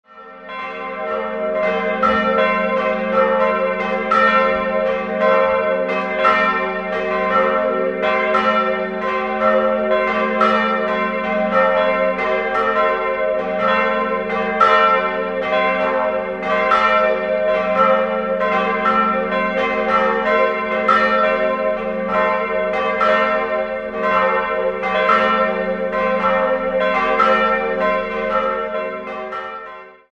3-stimmiges Gloria-Geläute: fis'-gis'-h'